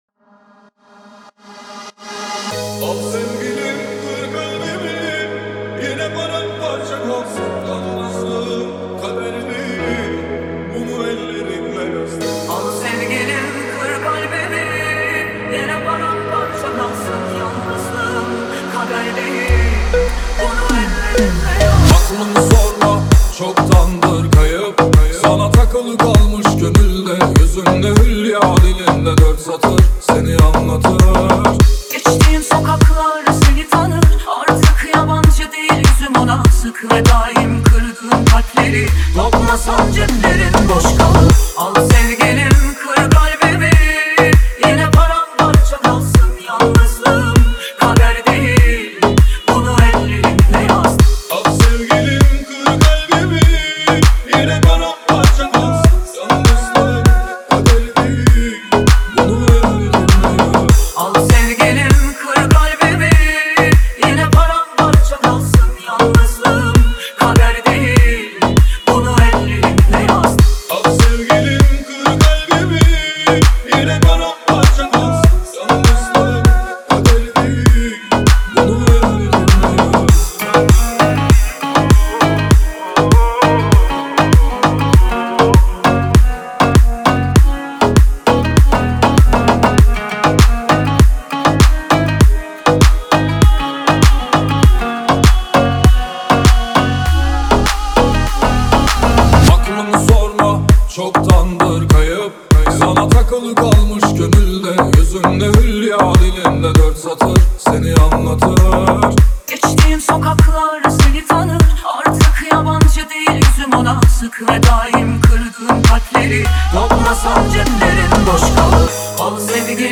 دانلود نسخه ریمیکس همین موزیک